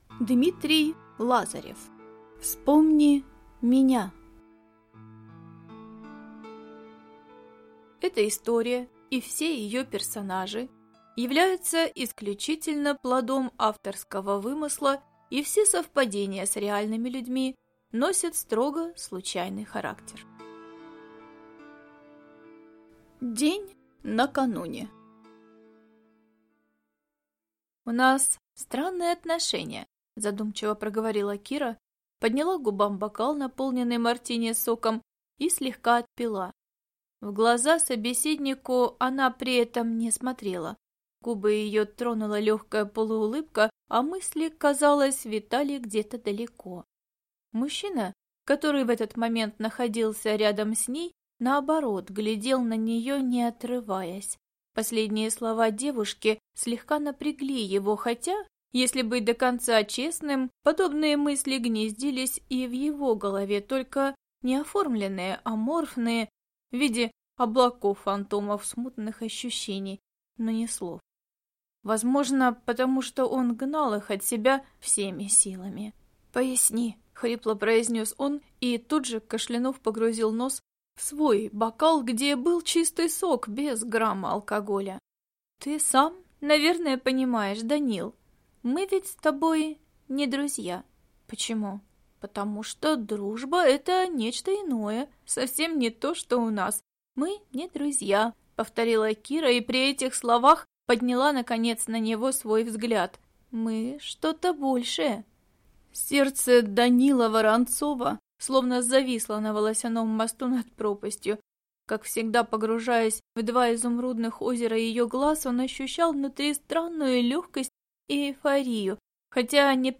Аудиокнига Вспомни меня | Библиотека аудиокниг
Прослушать и бесплатно скачать фрагмент аудиокниги